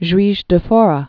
(zhwēzh də fôrə)